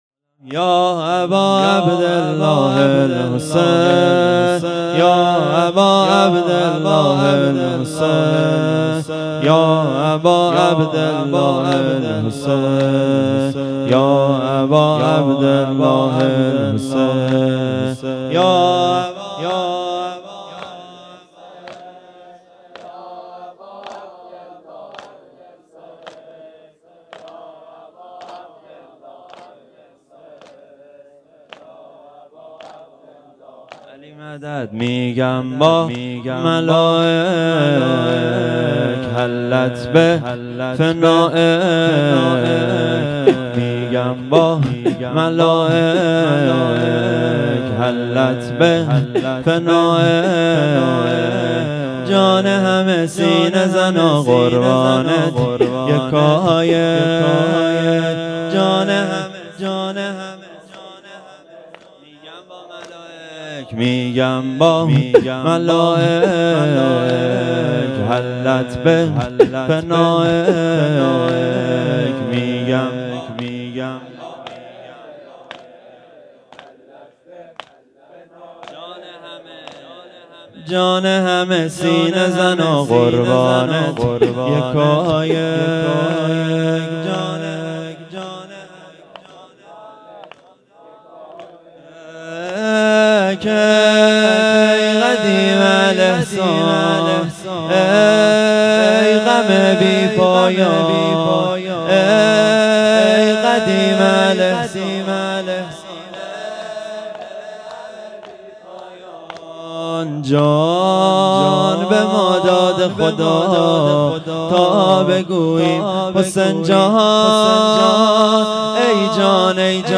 شب دوم محرم ۱۴۴۴